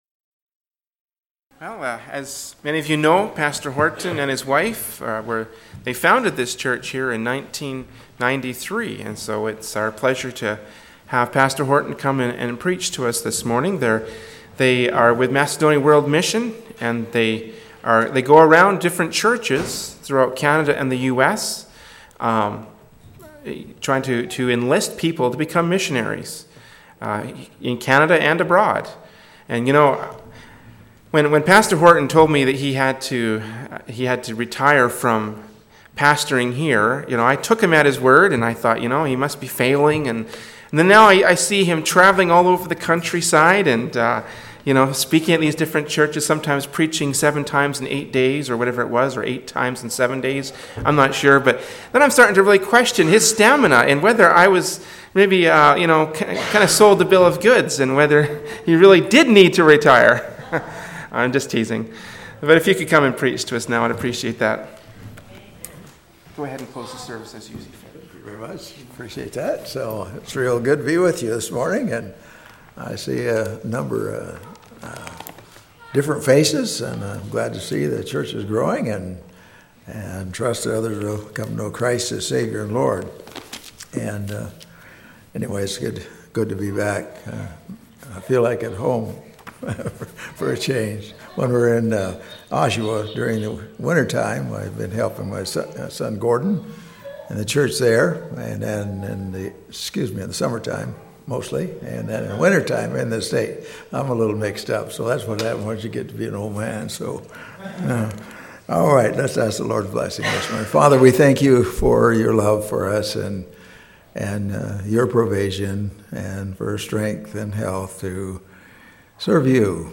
“The Work of Faith: Noah” from Sunday Morning Worship Service by Berean Baptist Church.